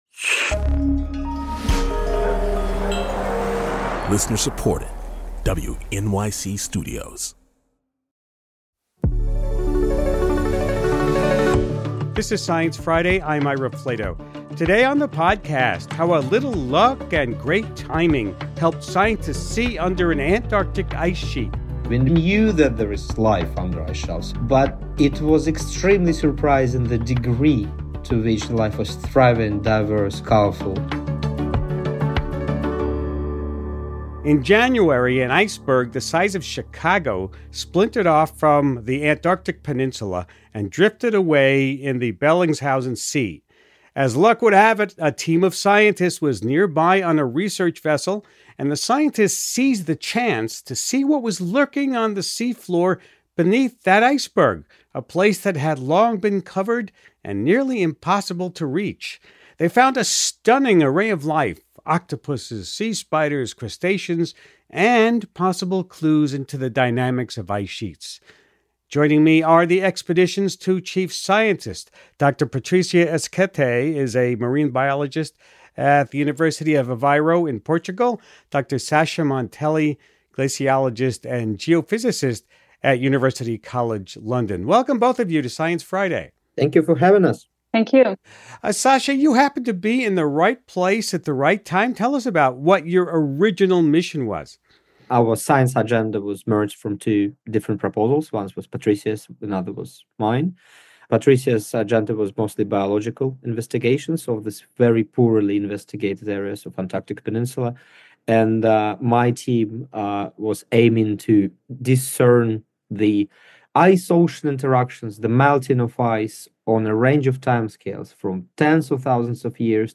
Host Ira Flatow talks with the expedition’s two chief scientists